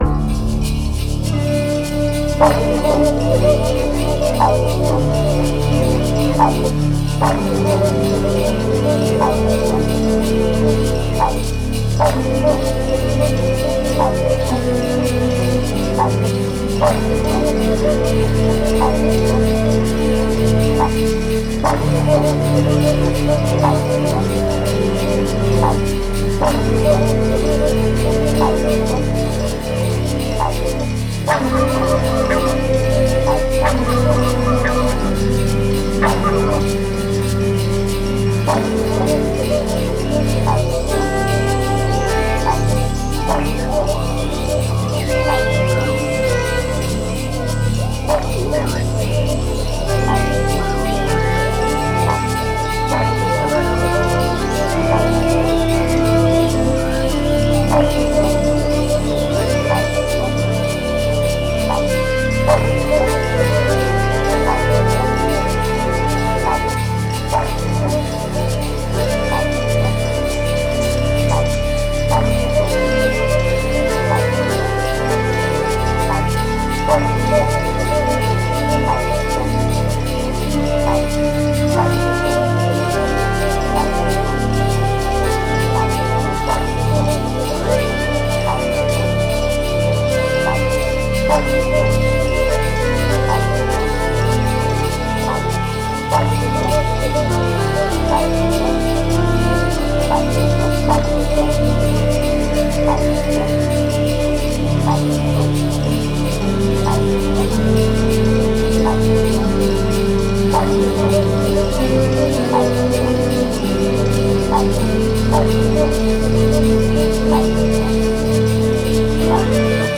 Etno /Creative Commons License 4.0 / noncommercial use free